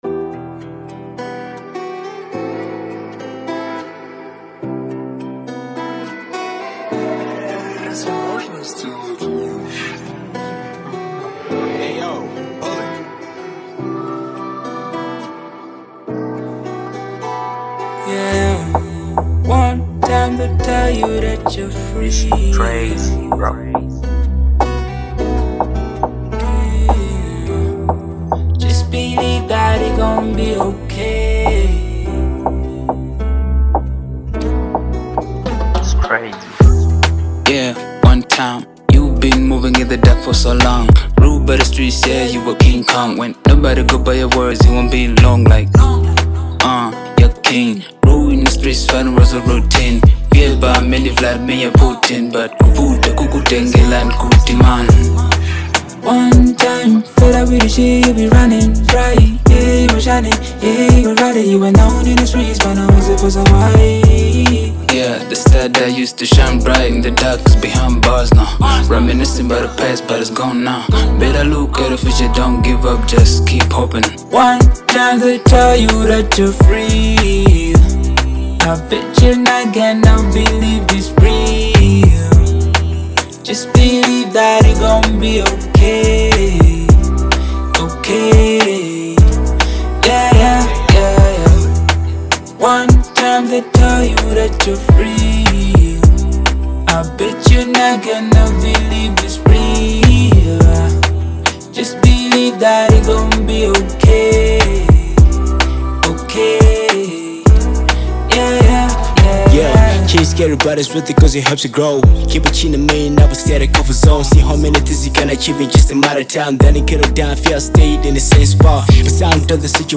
Genre : Hip hop/Dancehall